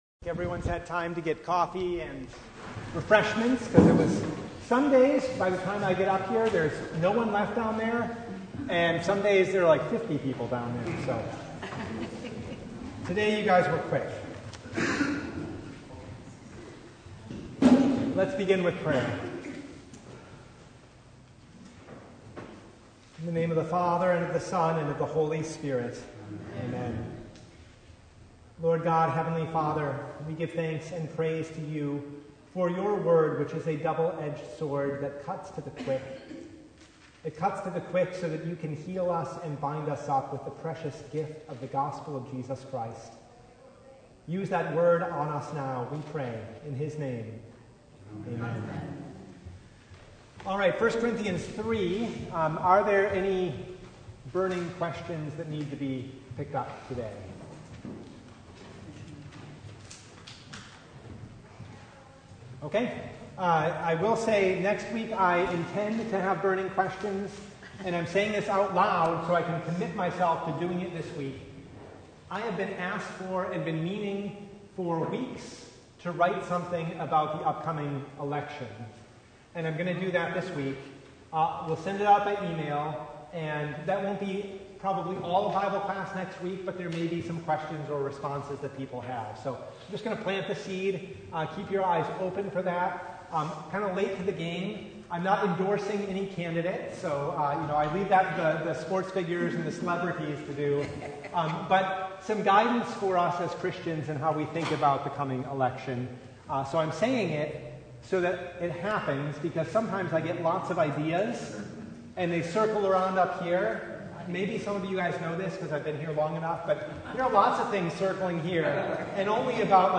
1st Corinthians 3:10-17 Service Type: Bible Hour Topics: Bible Study « Wealth